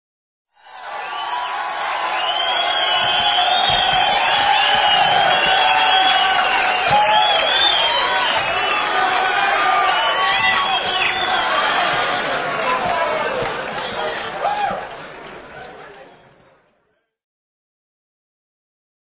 applause
Category: Sound FX   Right: Personal